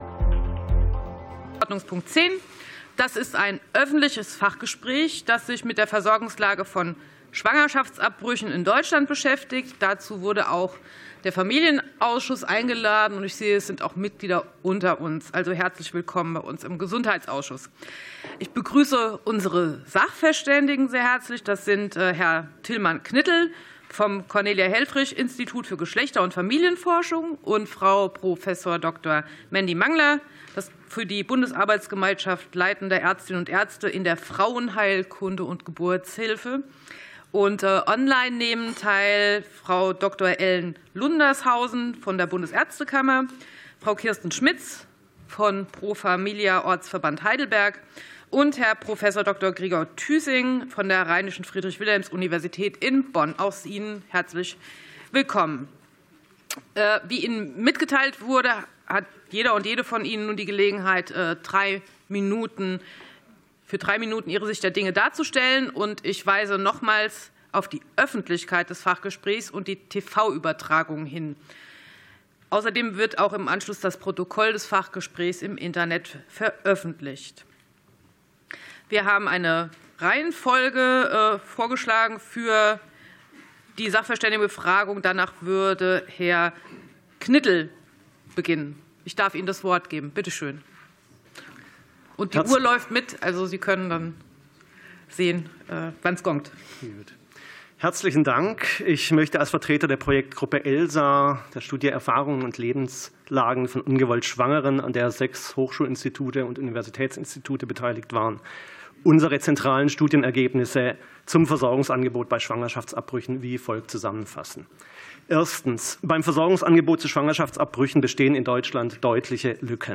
Fachgespräch des Ausschusses für Gesundheit